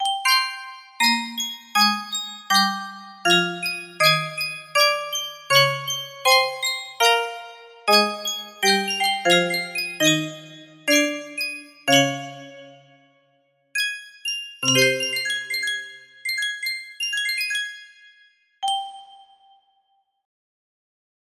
l1 music box melody
Full range 60